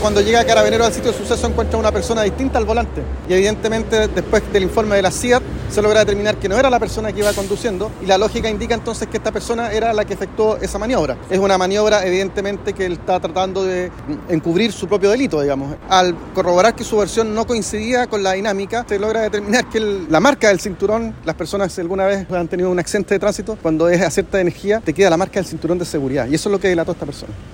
Así lo señaló el Fiscal jefe subrogante de la Fiscalía de Concepción, Jorge Lorca.